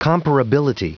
Prononciation du mot comparability en anglais (fichier audio)
Prononciation du mot : comparability